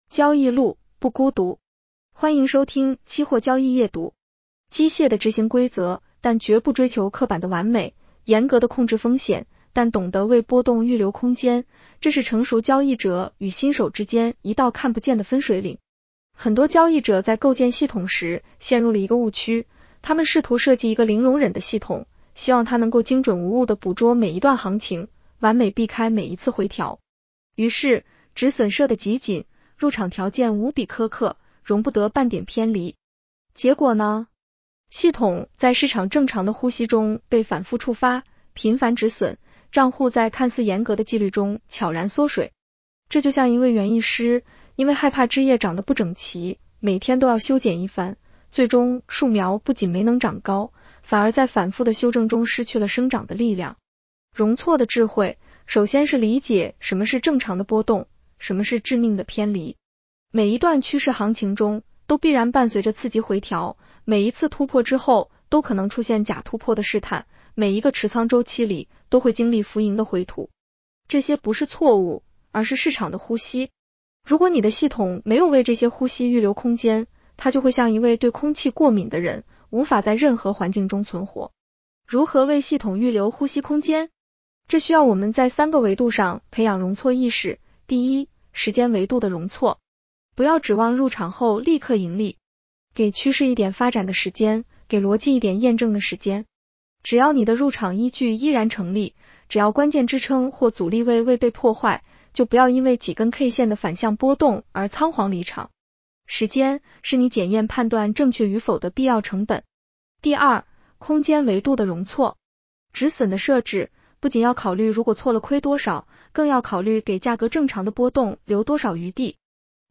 女声普通话版 下载mp3 交易路，不孤独。
（AI生成） 风险提示及免责条款：市场有风险，投资需谨慎。